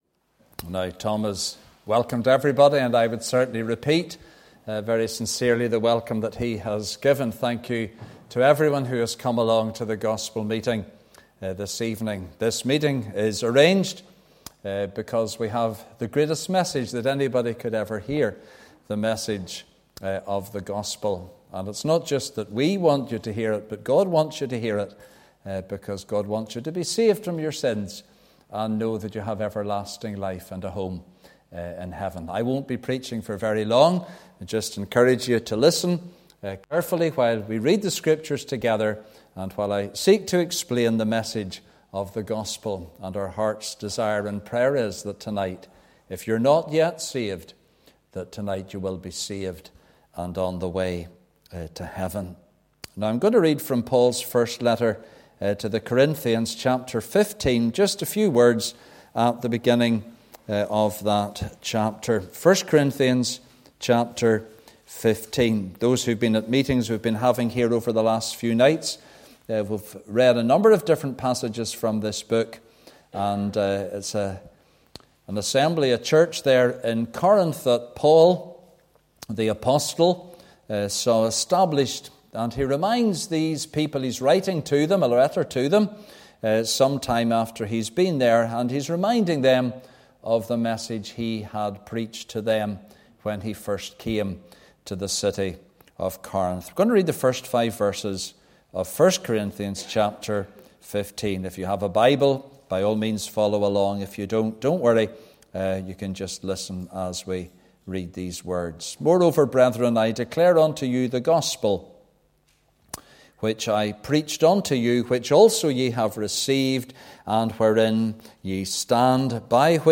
(Message preached Sunday 28th January 2024)